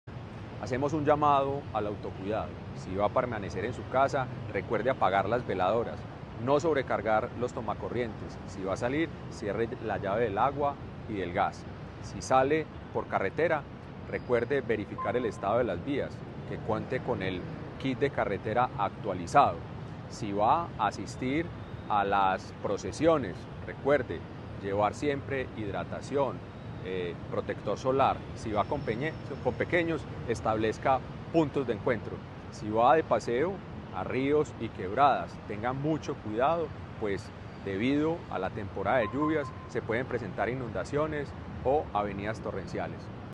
Palabras-de-director-del-DAGRD-Carlos-Andres-Quintero.mp3